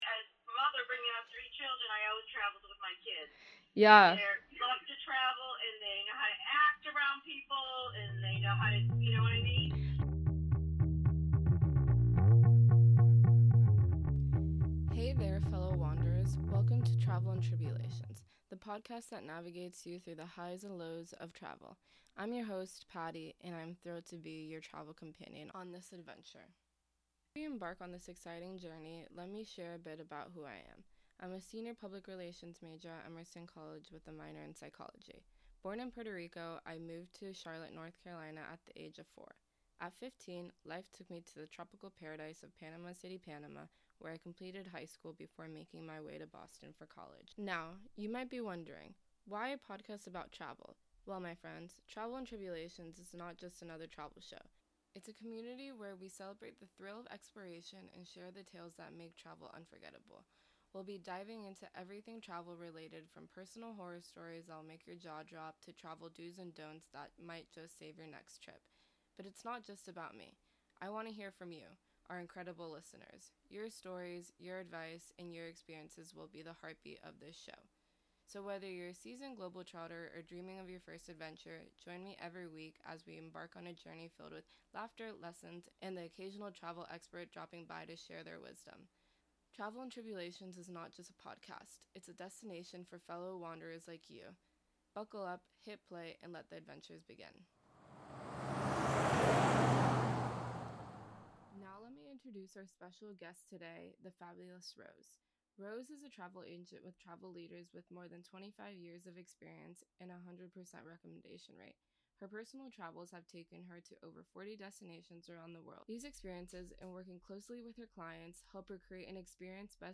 AD Read
Listener submission